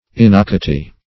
Innocuity \In"no*cu"i*ty\, n.